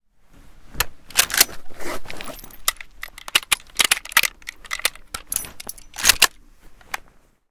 mosin_reload_empty.ogg